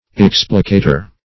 Search Result for " explicator" : The Collaborative International Dictionary of English v.0.48: Explicator \Ex"pli*ca`tor\, n. [L.] One who unfolds or explains; an expounder; an explainer.